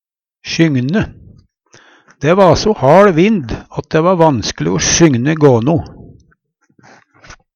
sjygne - Numedalsmål (en-US)